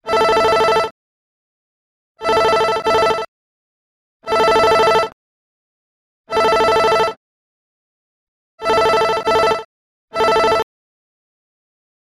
simple-office-tone_24781.mp3